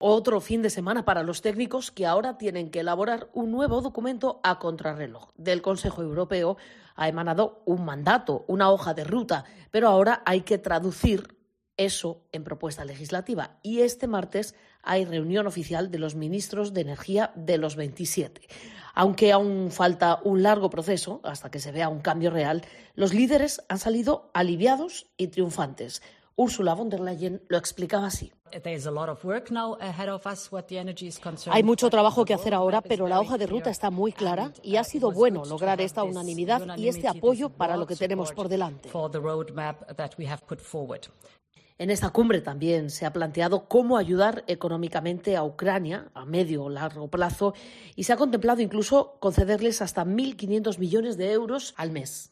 Paloma García Ovejero te explica los siguientes pasos a dar por Bruselas para abaratar el precio del gas